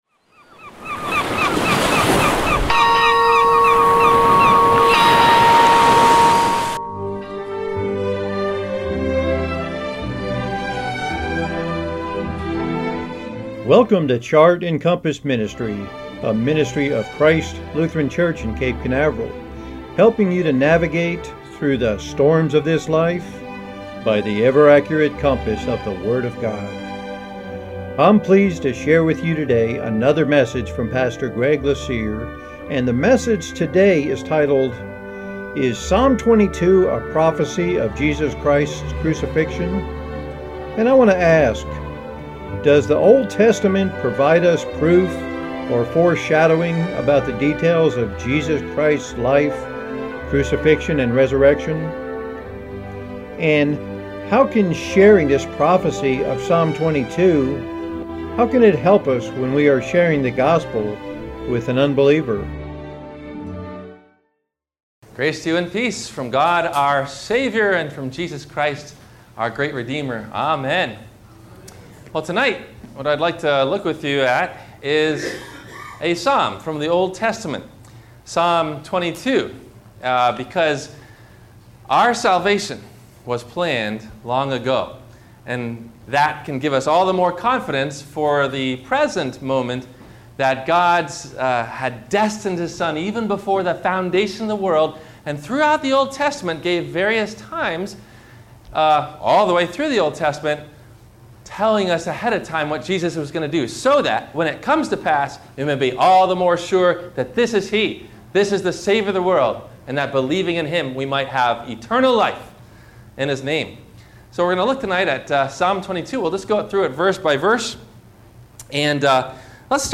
Is Psalm 22 a Prophecy of Jesus Christ’s Crucifixion? – WMIE Radio Sermon – February 22 2016